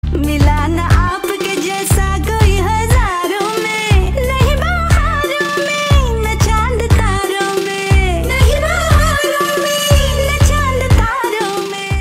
(Female) ringtone free download
Bollywood - Hindi